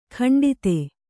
♪ khaṇḍite